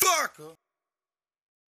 SouthSide Chant (34).wav